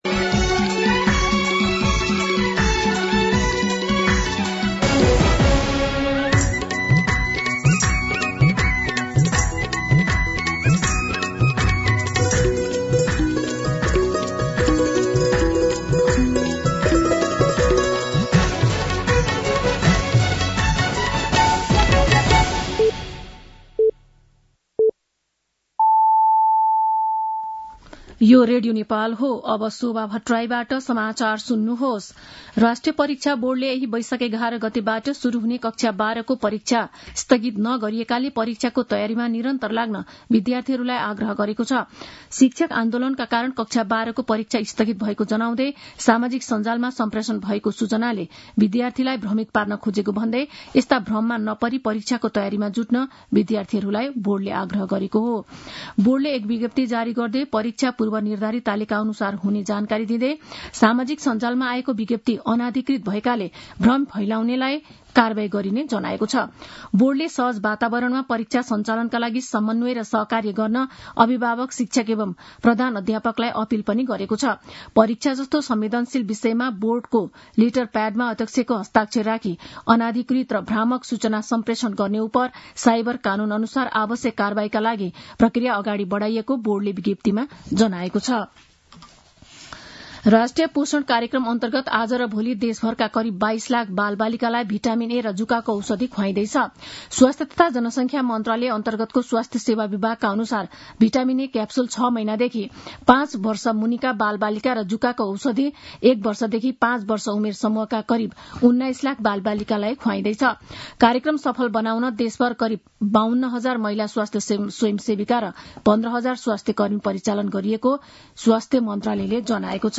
मध्यान्ह १२ बजेको नेपाली समाचार : ६ वैशाख , २०८२
12-pm-Nepali-News-5.mp3